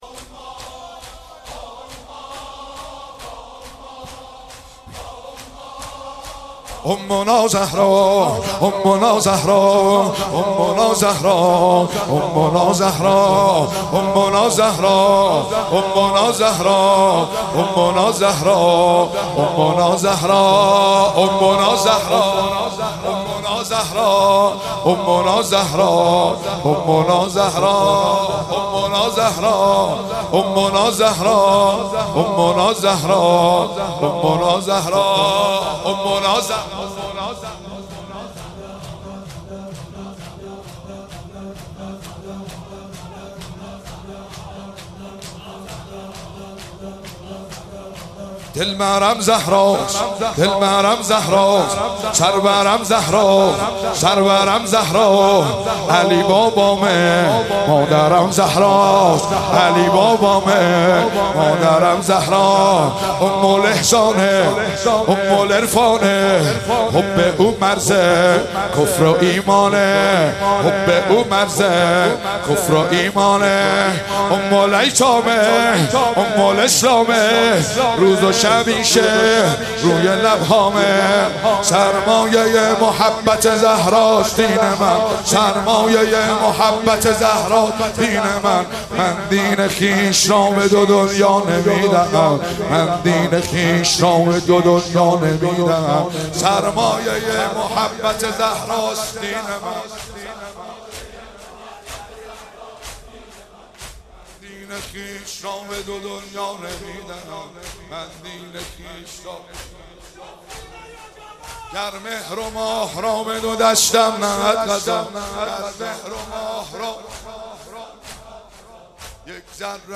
30 بهمن 96 - هیئت فاطمیون - شور - امنا زهرا،دلبرم زهرا